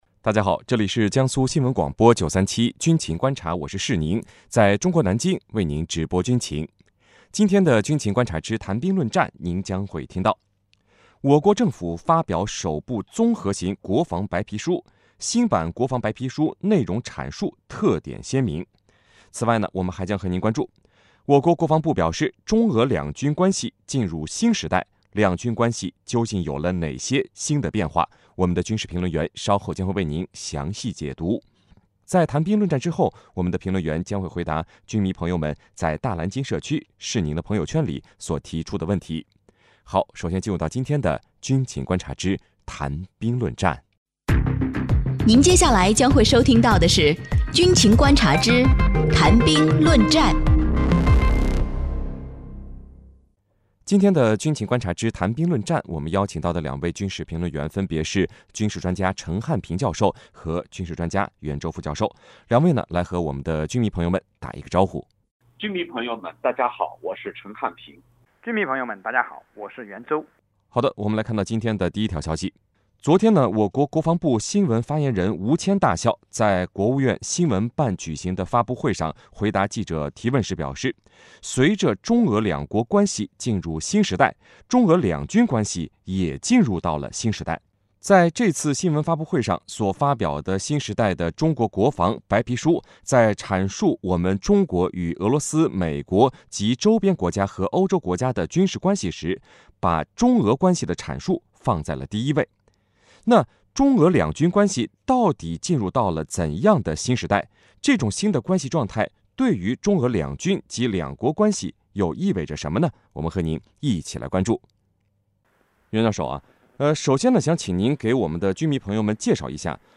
(来源:江苏新闻广播